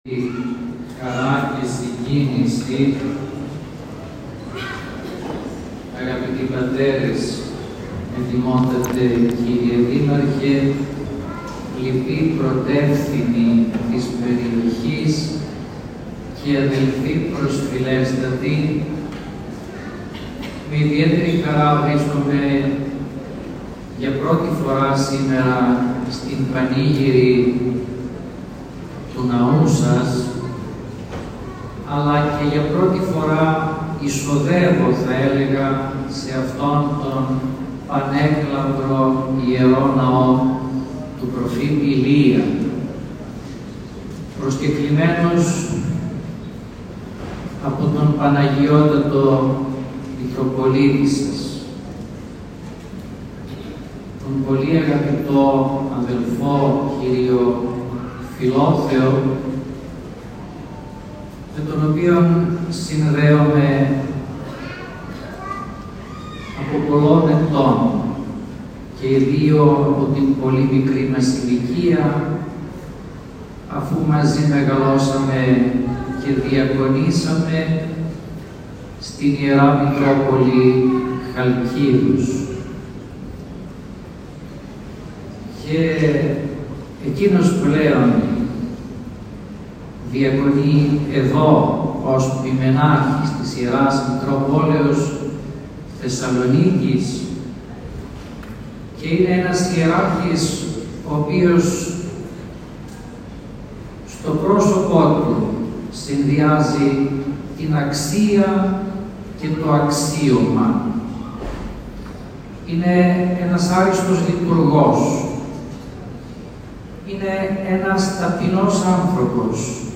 Η περιοχή φόρεσε τα γιορτινά της, ενώ δεκάδες πιστοί από την ευρύτερη περιοχή προσήλθαν από νωρίς το πρωί στον Ιερό Ναό του Προφήτου Ηλιού, συμμετέχοντας στην Αρχιερατική Θεία Λειτουργία, προκειμένου να λάβουν μέρος στο Μυστήριο της Θείας Ευχαριστίας και να τιμήσουν τον προστάτη τους.
Της Πανηγυρικής Θείας Λειτουργίας προεξήρχε ο Επίσκοπος Σκοπέλου κ. Νικόδημος.